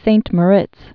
(sānt mə-rĭts, săɴ mô-rēts)